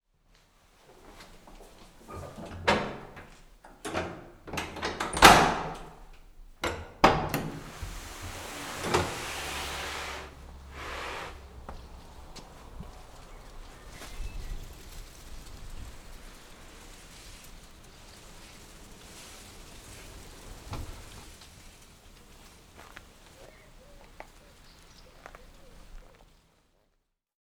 Toutefois, voici quelques exemples anonymes de sons qui ont accompagné un témoignage.
« Ah la porte du jardin qui s’ouvrait sur un monde à découvrir…
Porte-jardin.wav